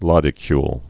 (lŏdĭ-kyl)